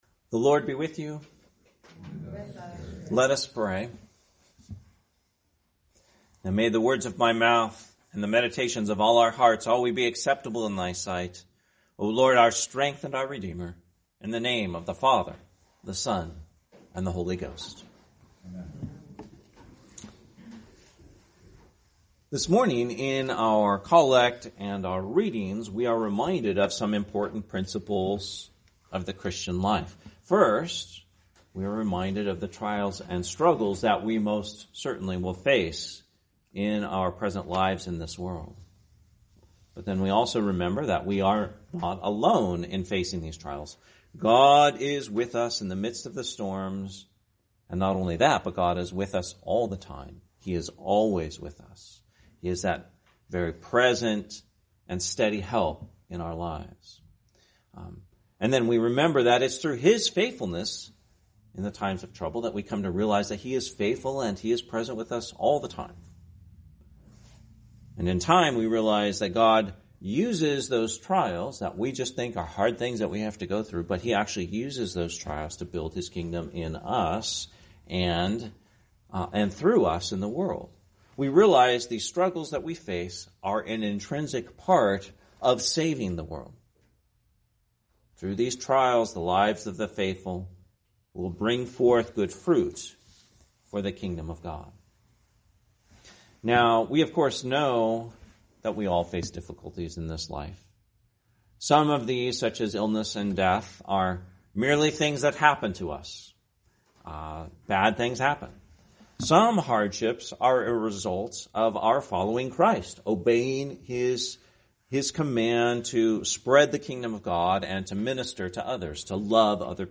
Sermon, Sexagesima Sunday, 2025